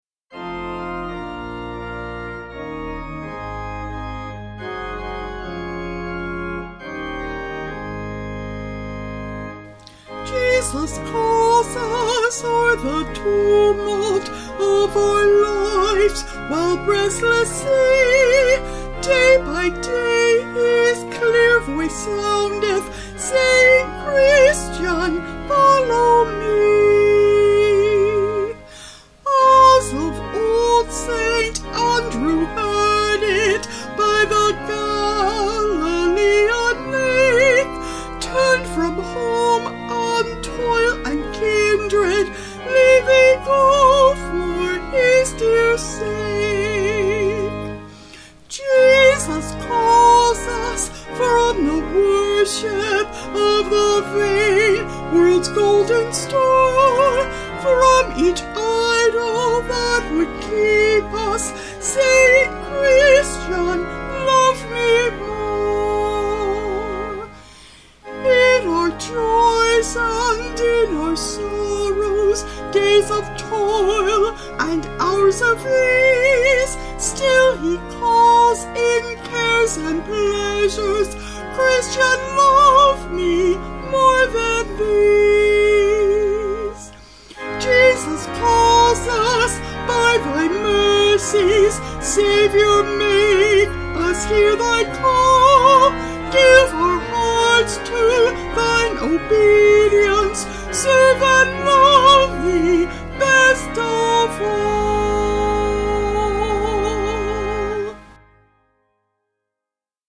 Vocals and Organ   225.6kb Sung Lyrics